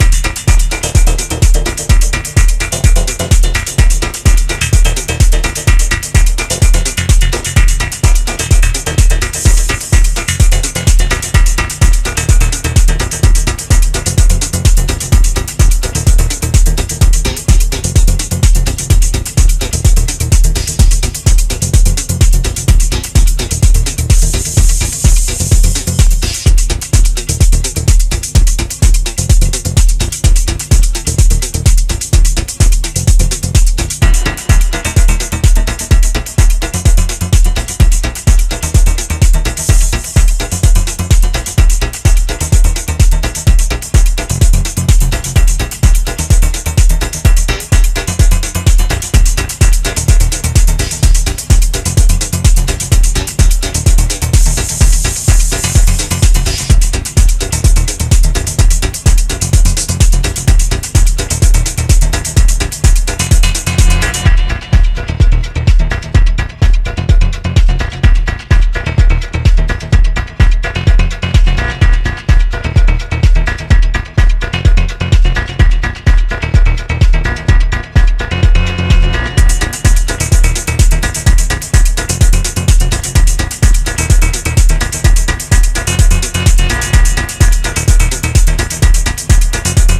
rattling, mechanical energy